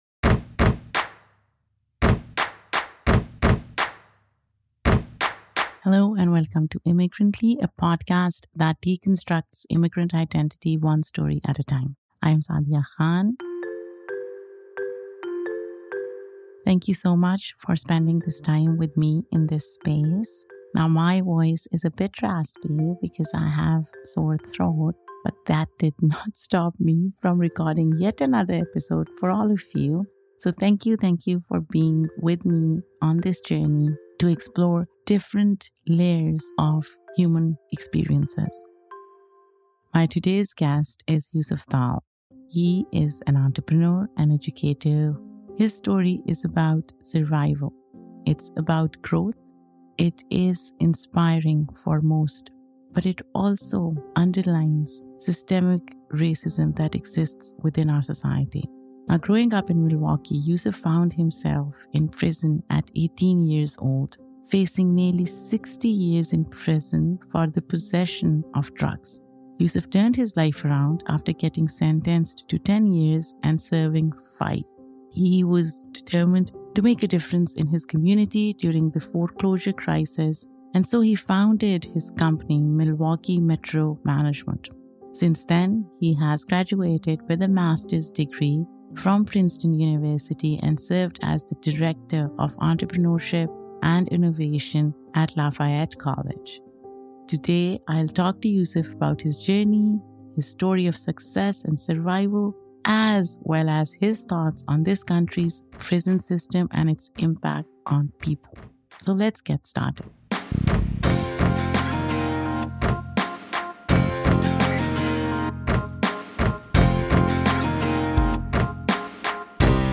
Hey folks, join me for a chat